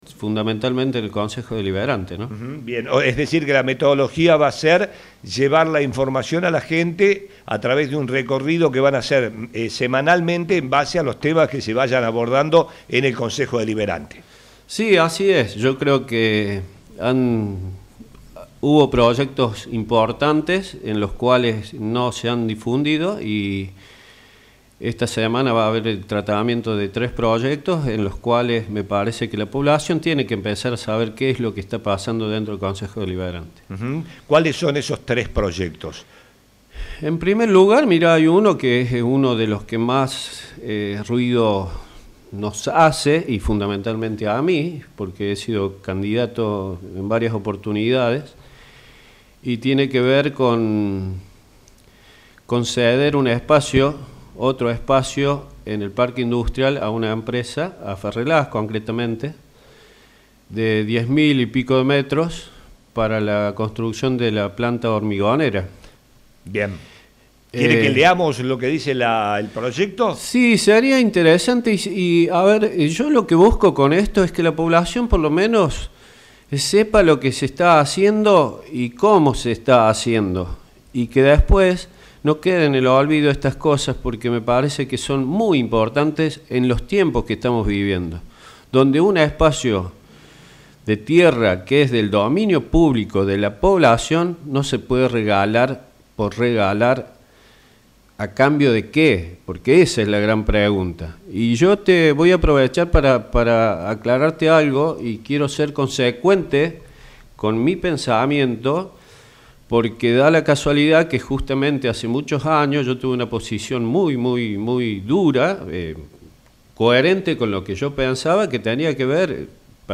visitó nuestros estudios